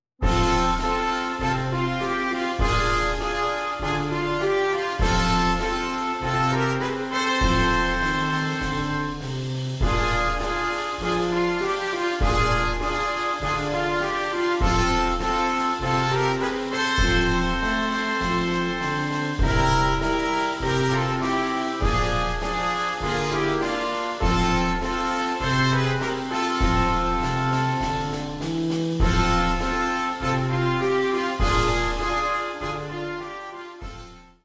simple twelve bar blues based melodies